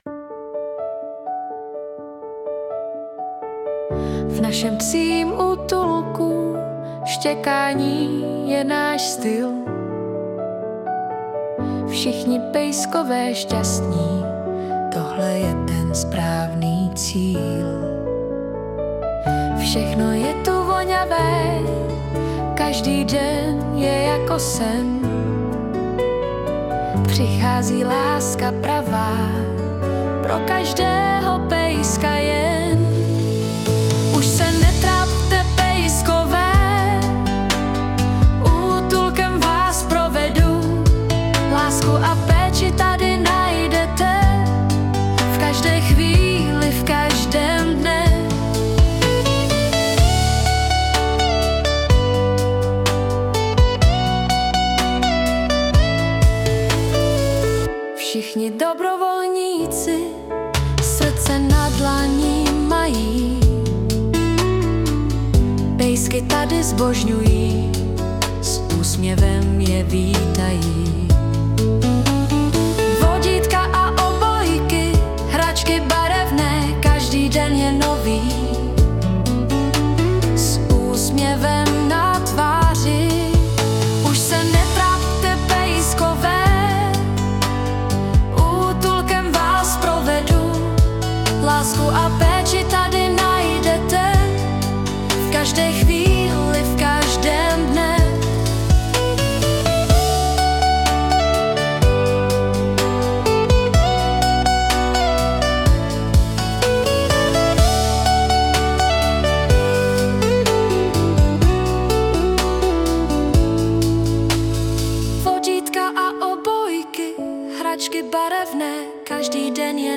Vytvořena v roce 2024, remasterována v roce 2025, suno 4.5.